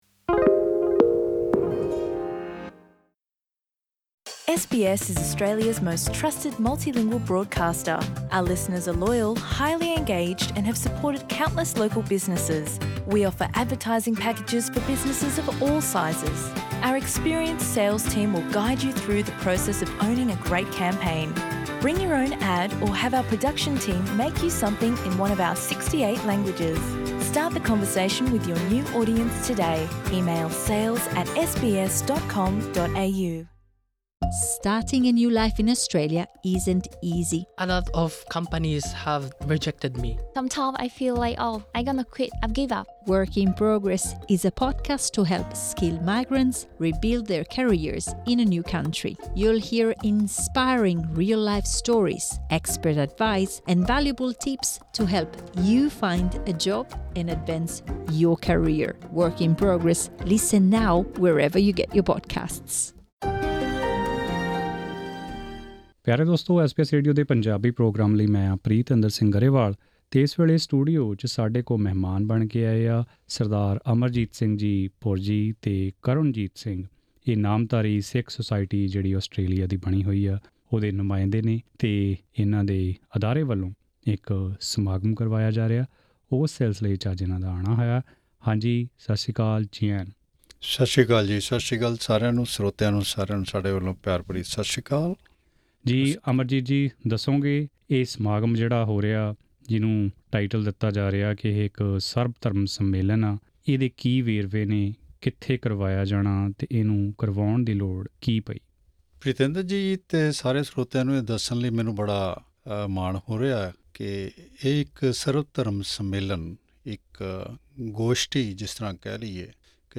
ਐਸ ਬੀ ਐਸ ਪੰਜਾਬੀ ਨਾਲ਼ ਇੱਕ ਇੰਟਰਵਿਊ